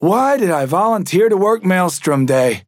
Trapper voice line - Why did I volunteer to work Maelstrom day?